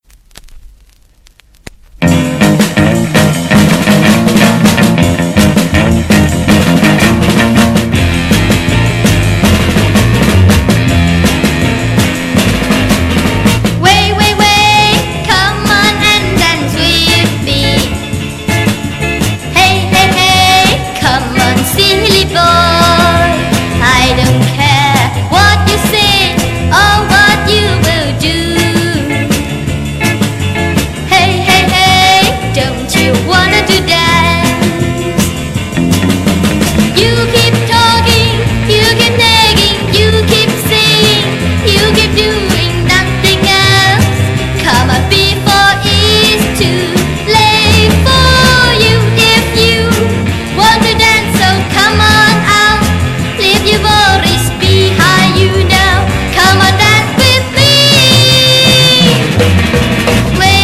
Genre: Rock, Pop
Style: Pop Rock, Cantopop, Hokkien Pop, Mandopop